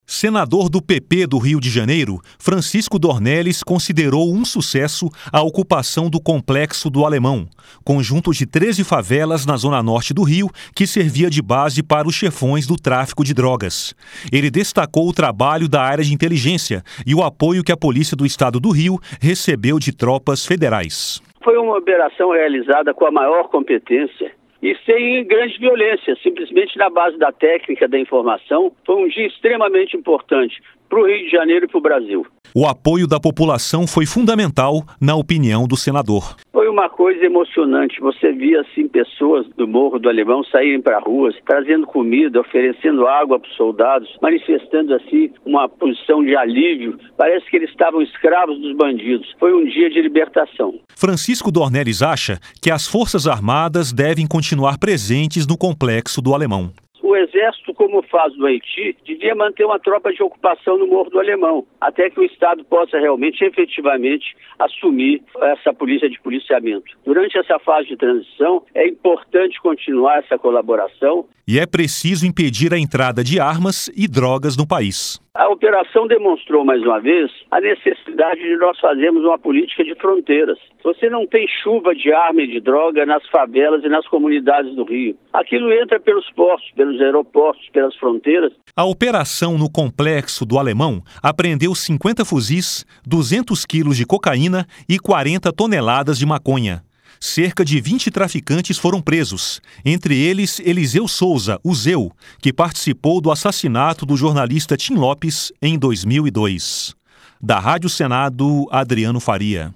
LOC: EM ENTREVISTA NESTA SEGUNDA-FEIRA À RÁDIO SENADO, DORNELLES DISSE QUE AGORA É PRECISO REFORÇAR AS FRONTEIRAS PARA IMPEDIR QUE TRAFICANTES RECEBAM ARMAS E DROGAS.